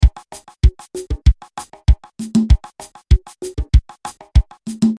Energetic Retro Mambo Dance Drum Loop 194 BPM
Description: Energetic retro Mambo dance drum loop 194 BPM.
Bring authentic Latin percussion, retro Cuban rhythms, and energetic 1950s Havana vibes into your commercial or non-commercial projects.
Genres: Drum Loops
Tempo: 194 bpm
Energetic-retro-mambo-dance-drum-loop-194-bpm.mp3